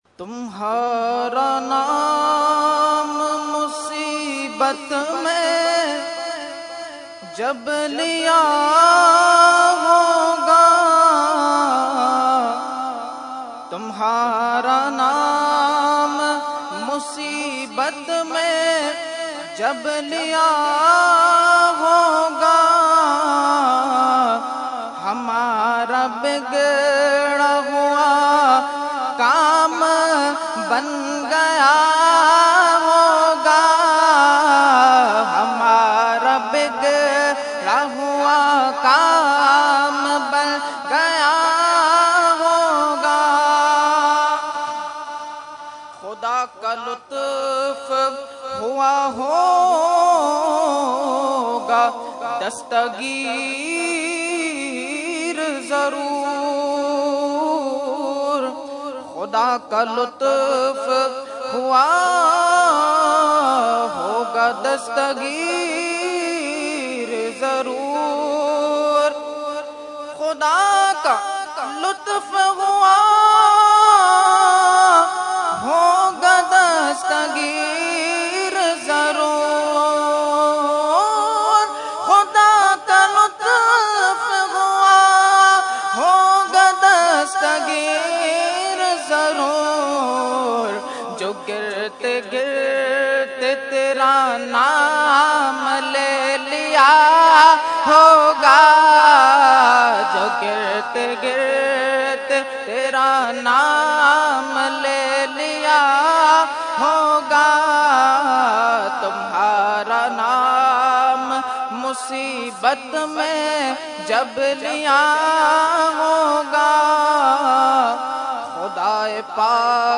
Category : Naat | Language : UrduEvent : Urs Ashraful Mashaikh 2015